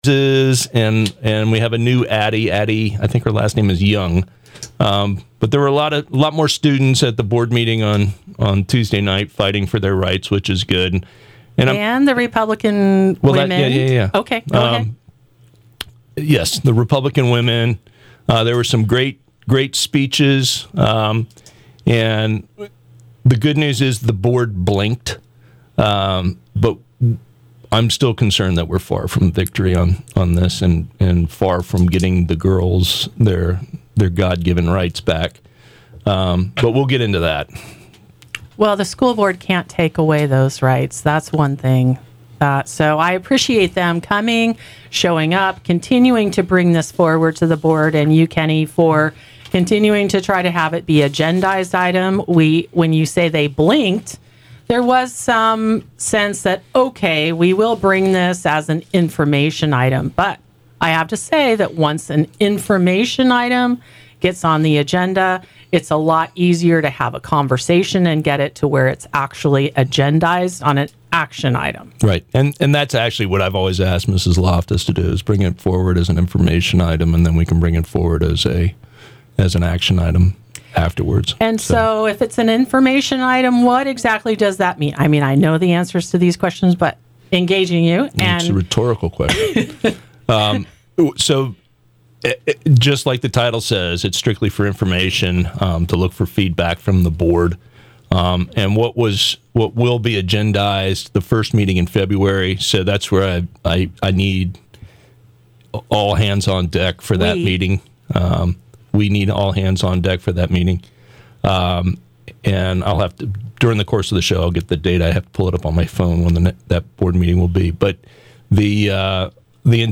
Call 805-238-KPRL (5775) to join in and let your opinion be heard.